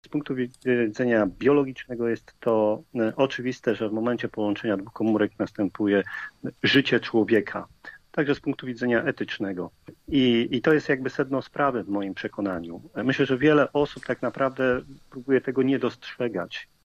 W Rozmowie po 9 odniósł się też do samej idei protestu, wskazując na kwestię życia człowieka: